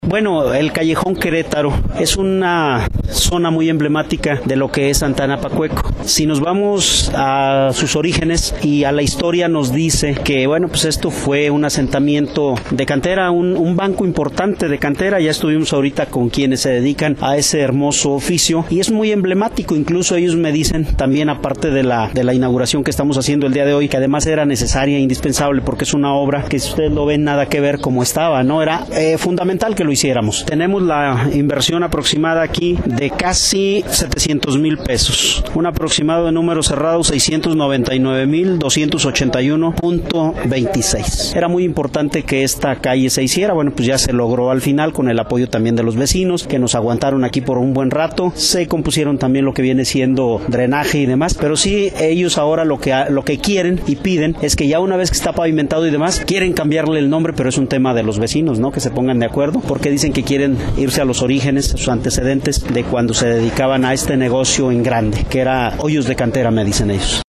Ayer por la tarde el presidente municipal de Pénjamo Guanajuato, Jacobo Manríquez Romero visitó la delegación de Santa Ana Pacueco, para inaugurar oficialmente el callejó Querétaro, estas fueron sus palabras en este evento.